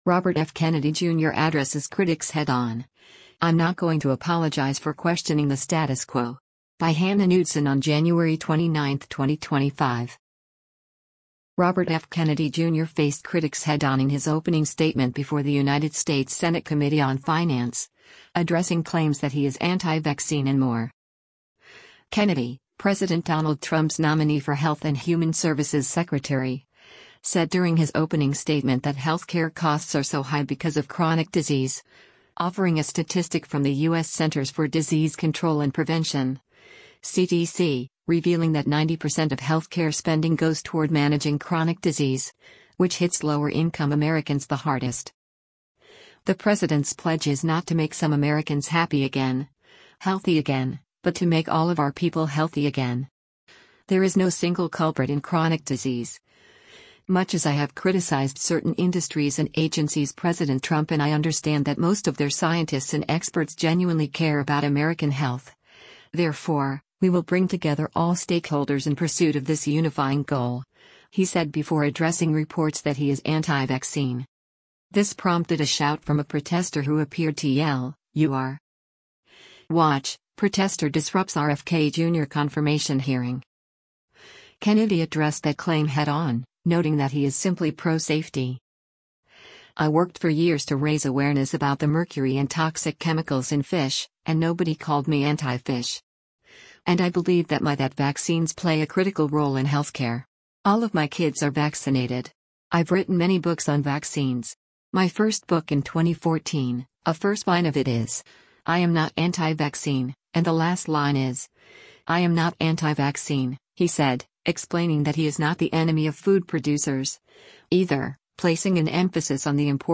Robert F. Kennedy Jr. faced critics head-on in his opening statement before the United States Senate Committee on Finance, addressing claims that he is “anti-vaccine” and more.
This prompted a shout from a protester who appeared to yell, “You are!”